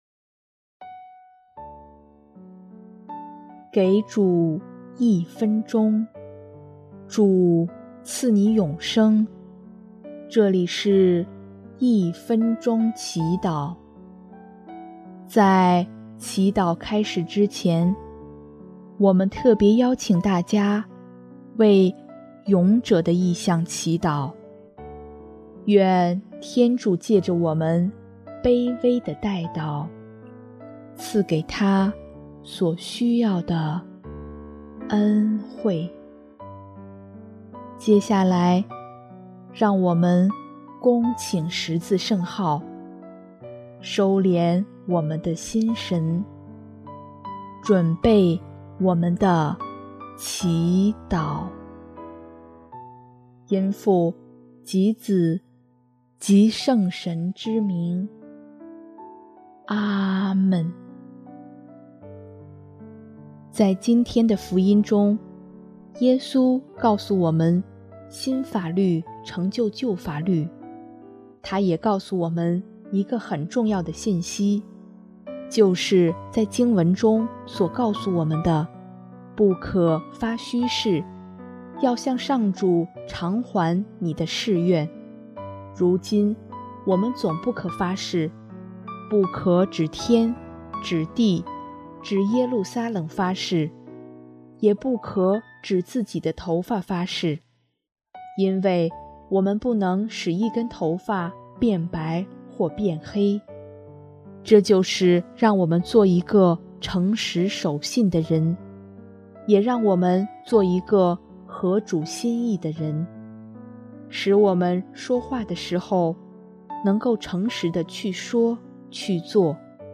【一分钟祈祷】|6月15日 做诚实守信合主心意的人